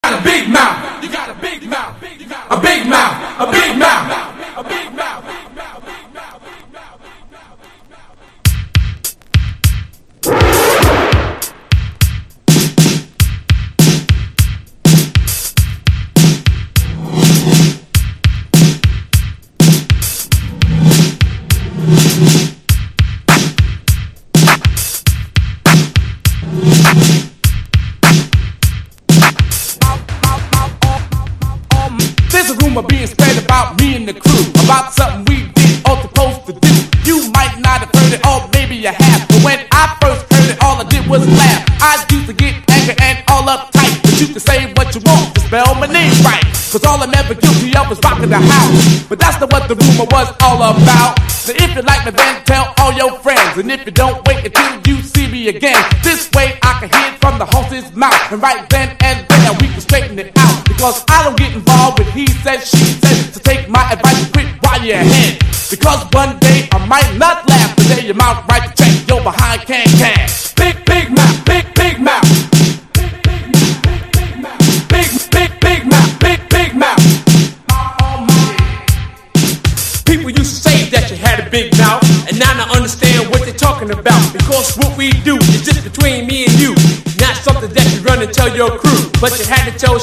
エレクトロサウンド炸裂のオケにキレの良いライミングが最高にかっこいいニュージャックスウィング！
所によりノイズありますが、リスニング用としては問題く、中古盤として標準的なコンディション。